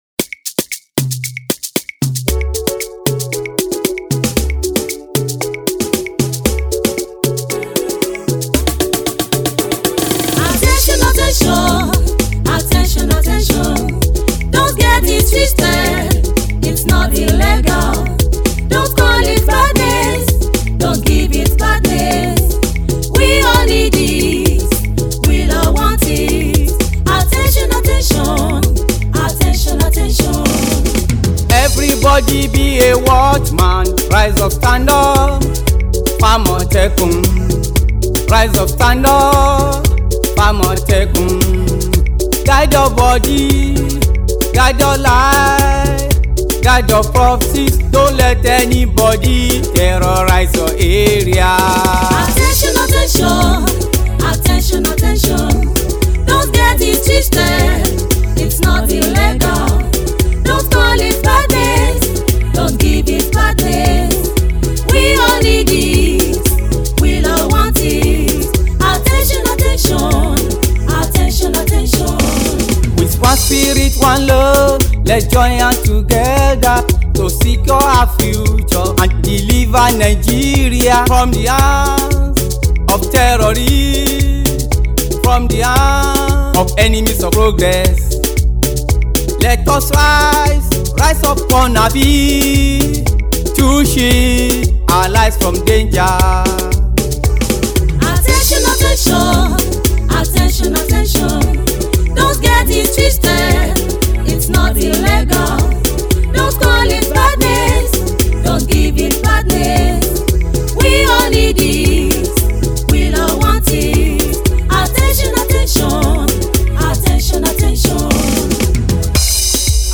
pleasant to hear and dance able.